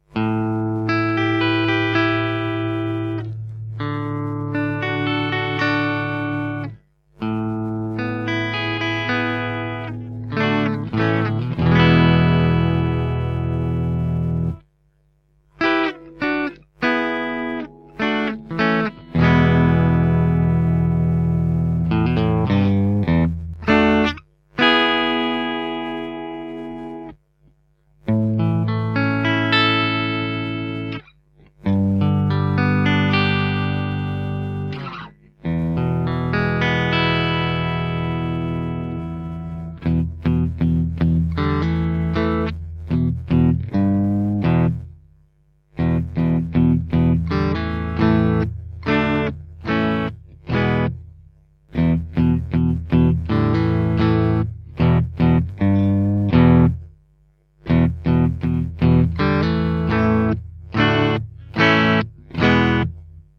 The Yellow Dog offers more bite and punch in the top end, together with plenty of warmth in the lower registers.